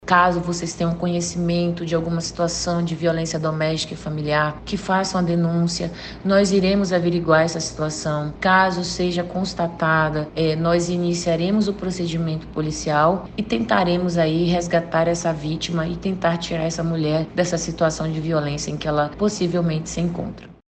A titular da especializada reforça que as vítimas de violência doméstica podem procurar qualquer um dos Distritos Integrados de Polícia (DIPs) na capital e interior, e enfatizou a importância de não se omitir diante de suspeitas de violência contra a mulher.